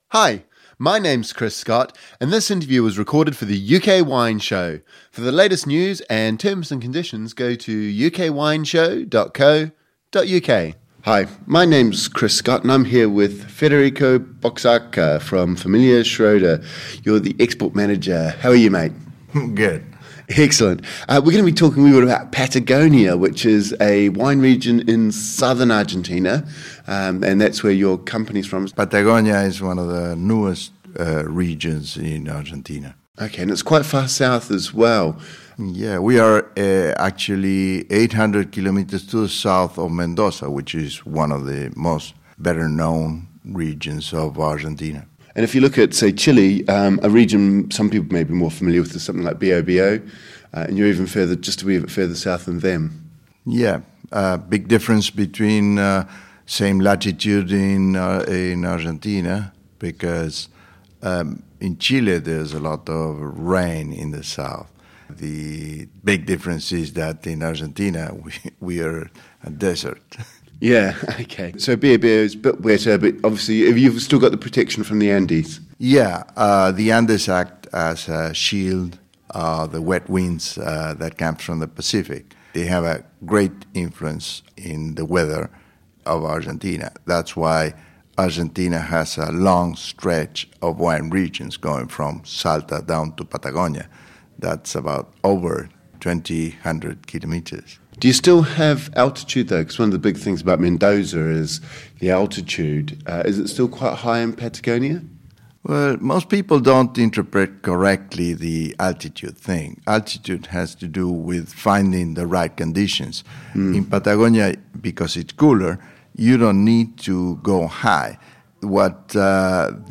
UK Wine Show » Listen to the interview only